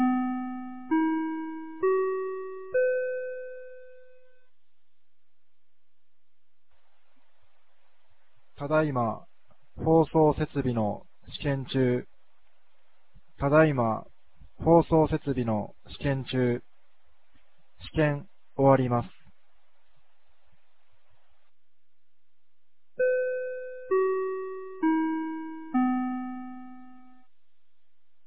2025年09月27日 16時03分に、由良町から全地区へ放送がありました。